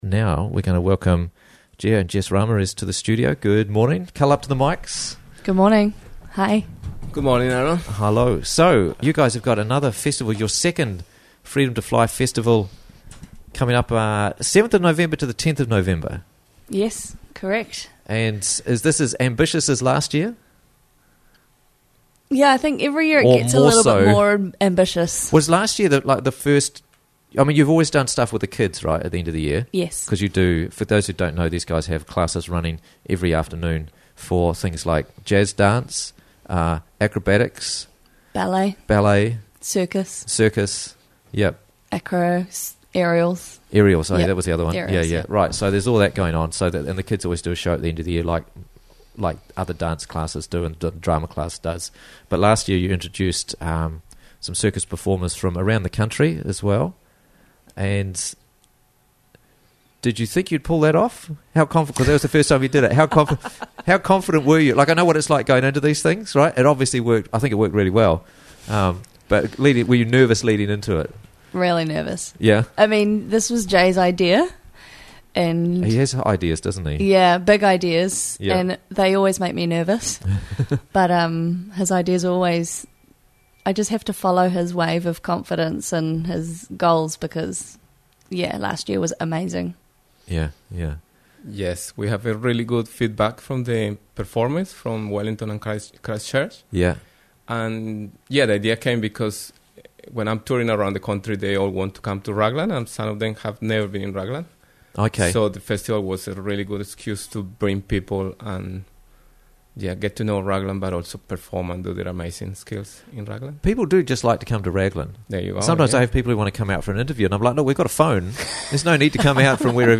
- Interviews from the Raglan Morning Show